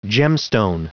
Prononciation du mot gemstone en anglais (fichier audio)
Prononciation du mot : gemstone